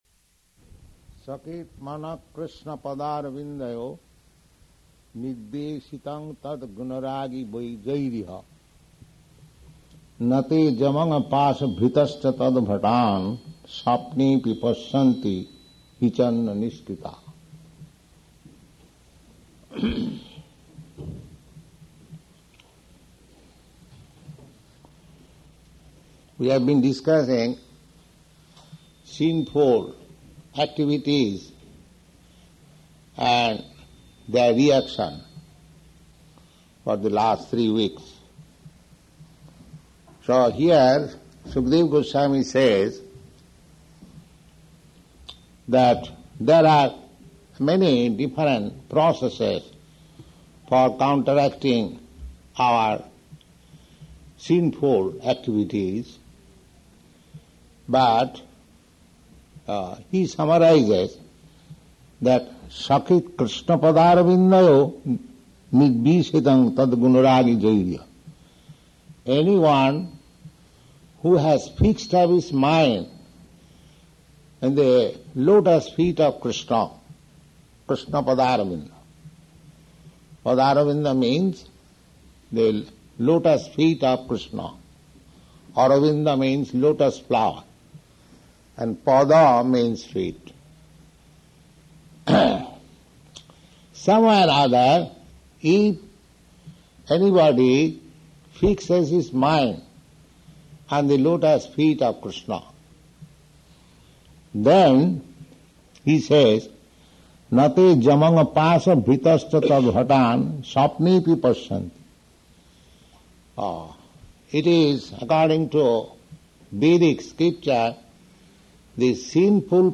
Type: Srimad-Bhagavatam
Location: Los Angeles